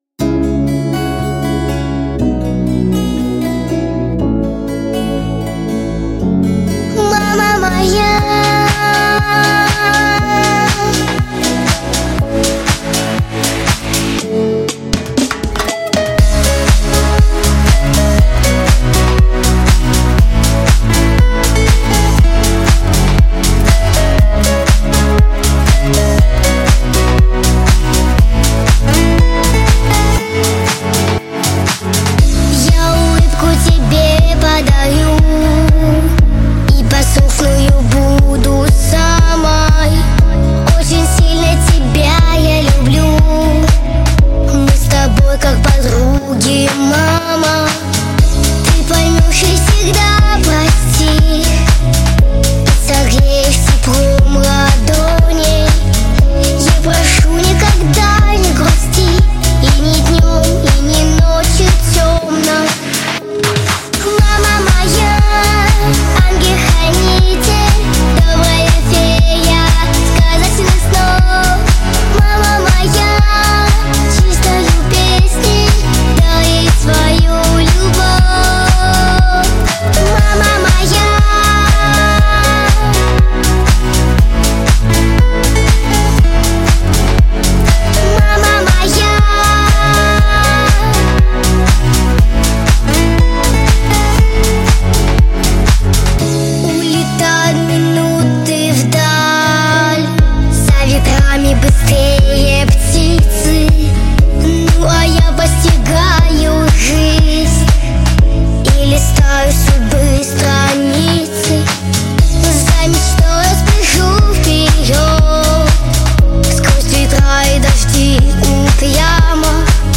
• Качество: Хорошее
• Жанр: Детские песни
Детская песня